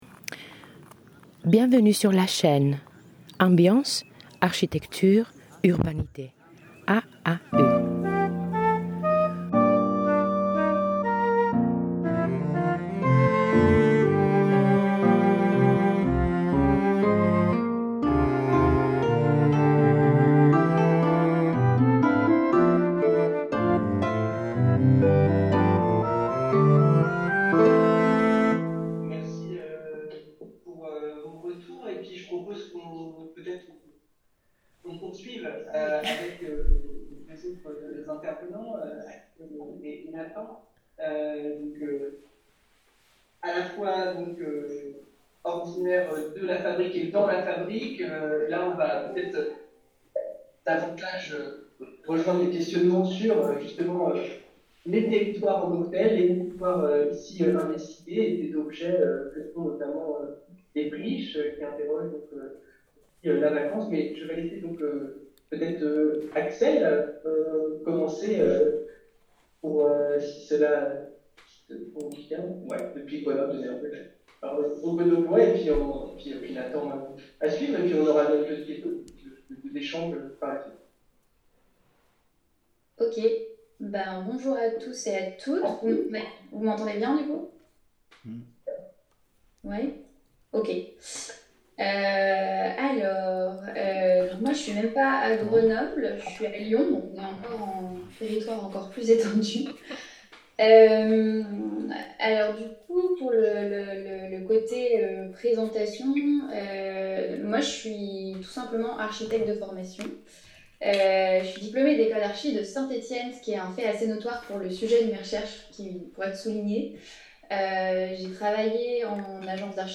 Table ronde 1 : L’ordinaire en ses objets et ses terrains - 2ème partie | Canal U